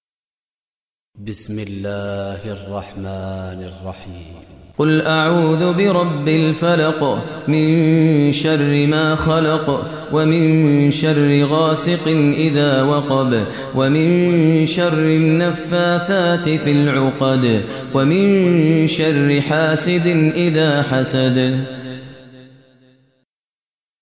Surah Falaq Recitation by Maher Mueaqly
Surah Falaq, listen online mp3 tilawat / recitation in Arabic recited by Imam e Kaaba Sheikh Maher al Mueaqly.